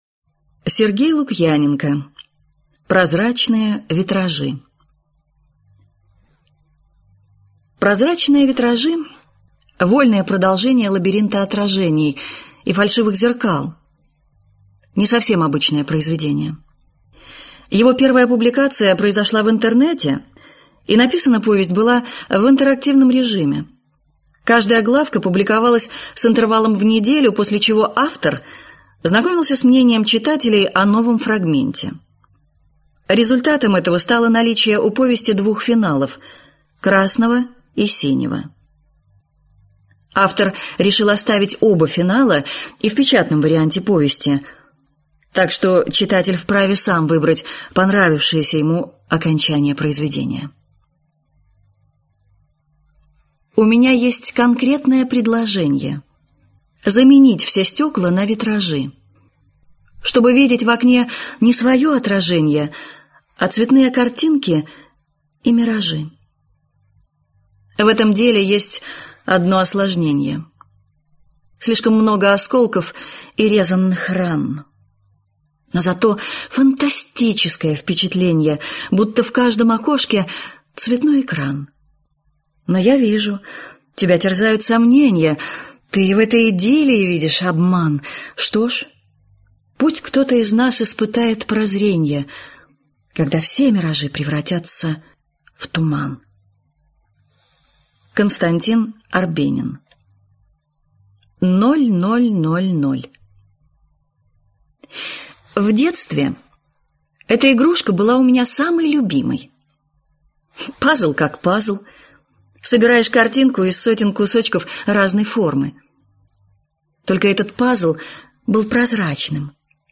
Аудиокнига Прозрачные витражи | Библиотека аудиокниг